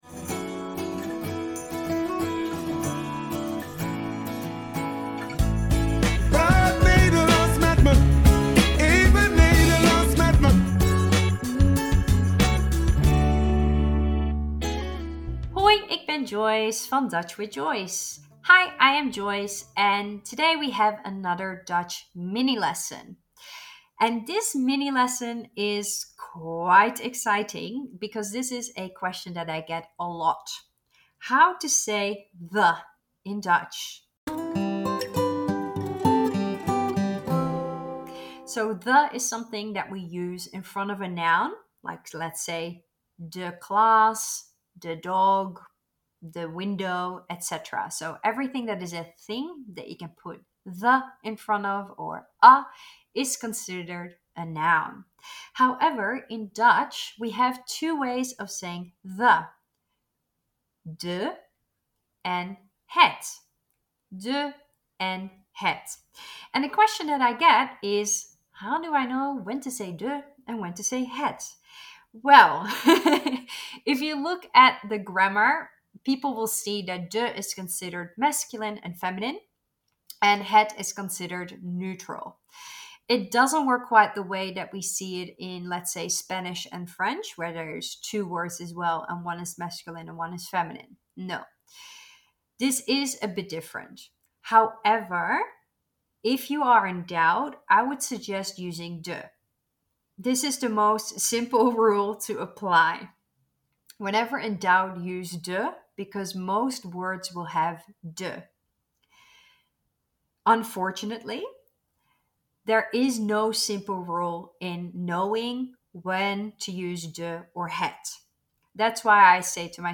In this podcast series you will learn a few Dutch words and pronunciations every week in a few minutes.